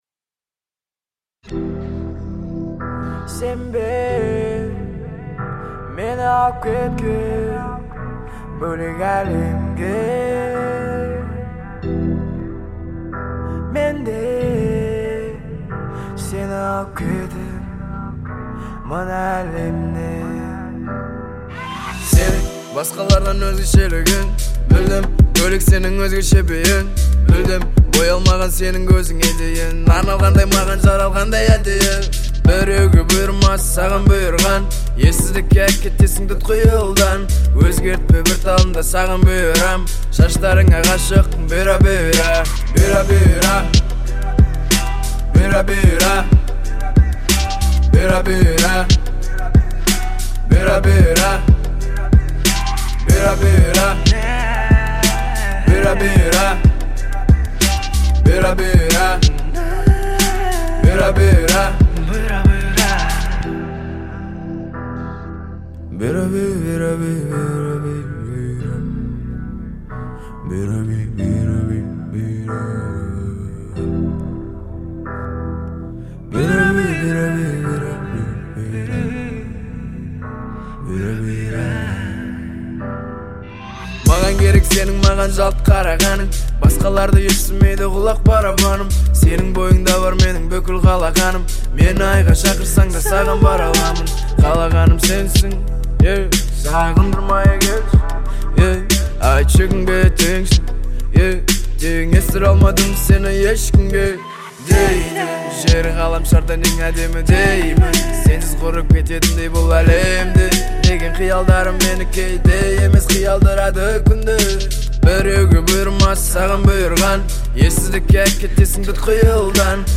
это яркая композиция в жанре поп с элементами этно